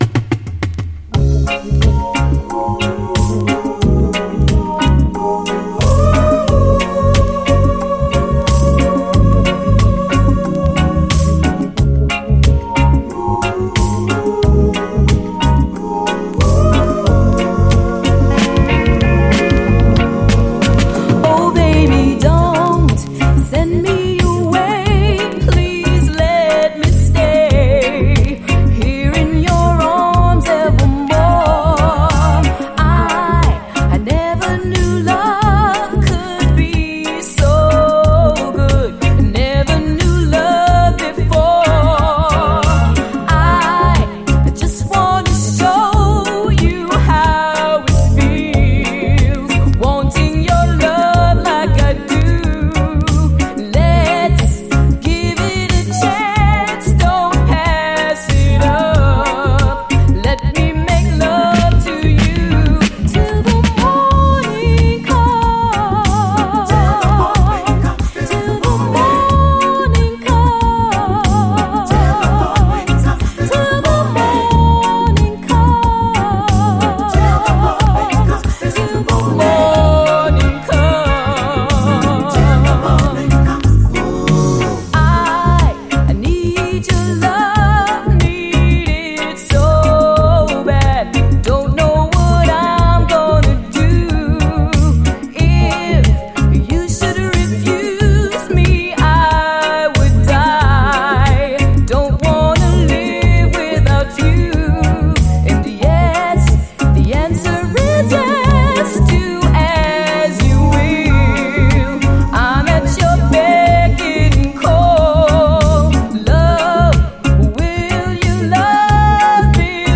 REGGAE
NY産最高ラヴァーズ・ロック！
マイナー女性シンガーによる、US、NY産最高ラヴァーズ・ロック！